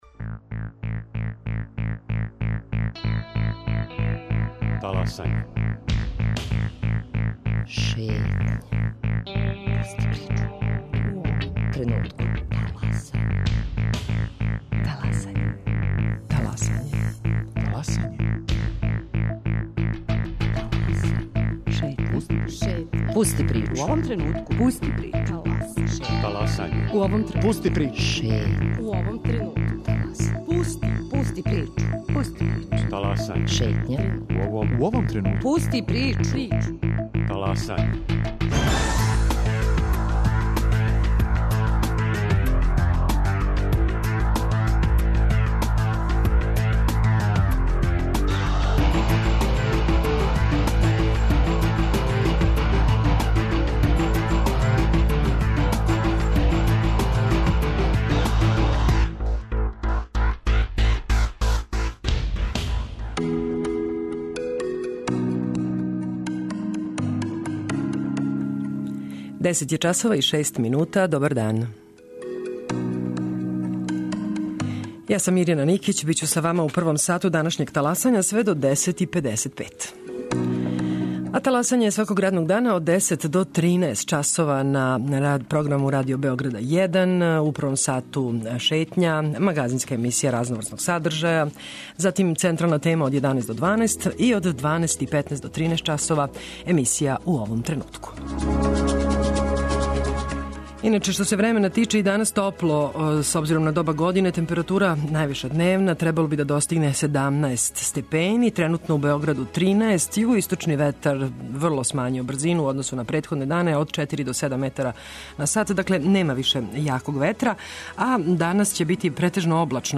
Гошће Шетње долазе из Аутономног женског центра, организације која учествује у кампањи "Потписујем".